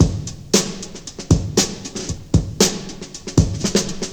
116 Bpm '00s Drum Groove G Key.wav
Free breakbeat - kick tuned to the G note.